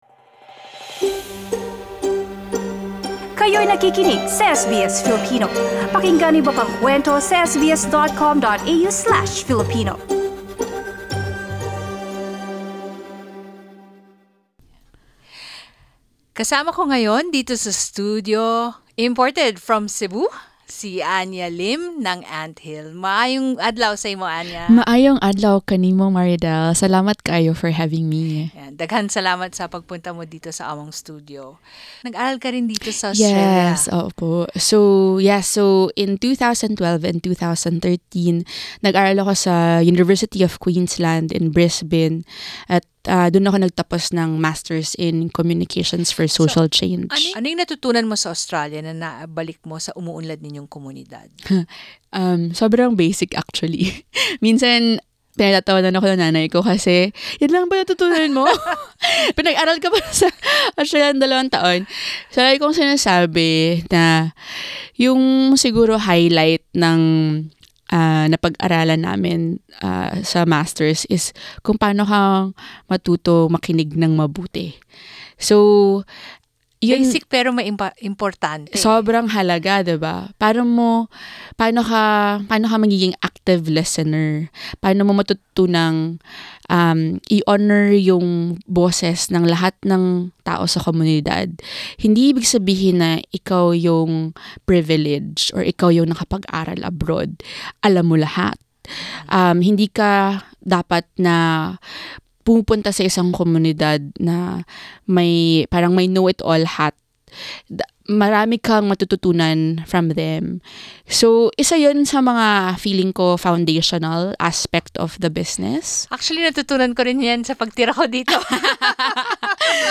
Sa pamamgitan ng paghahabi, nabuo ang mga oportunidad para sa mga kababaihang maging entrepreneur at ang pinaka mahalaga sa lahat ay ang oportunidad na maibahagi ang mahalagang tradisyon ng paghabi sa susunod na salin lahi. Narito ang ating panayam